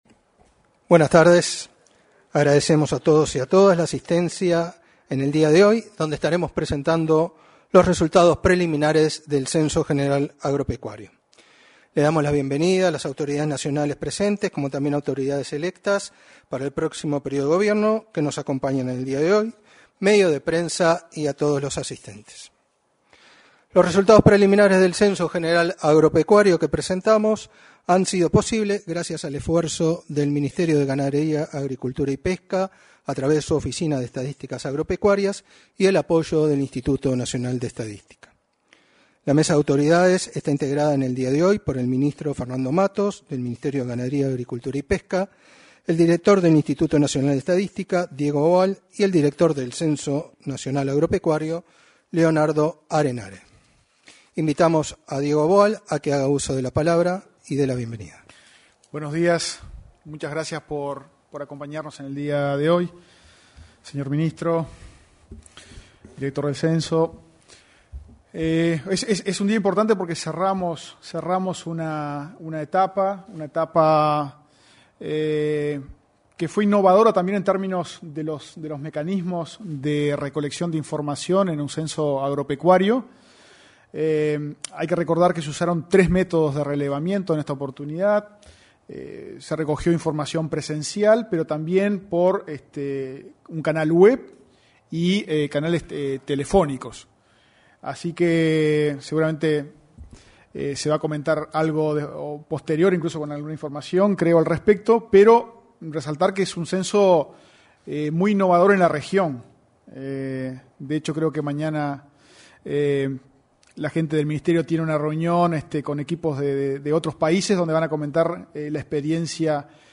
Este martes 25, se realizó, en el salón de actos de la Torre Ejecutiva la presentación de los resultados preliminares del Censo Agropecuario.